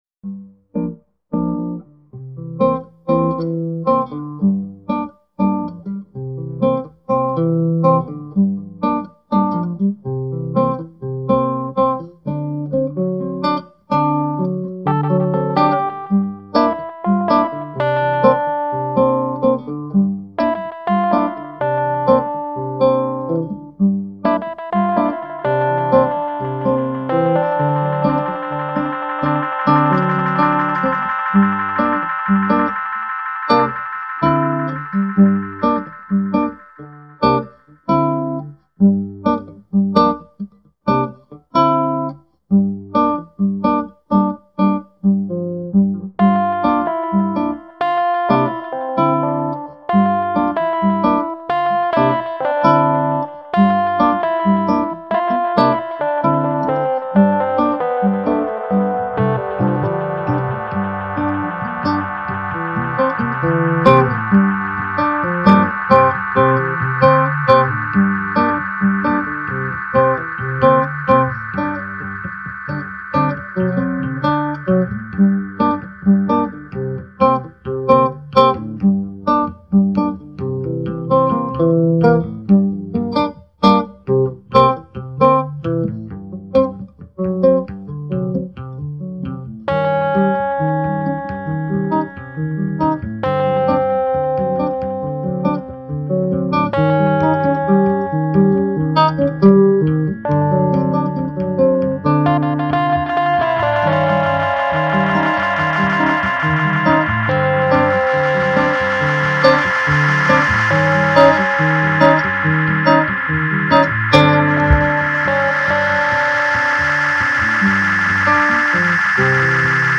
dance/electronic
NuJazz
World music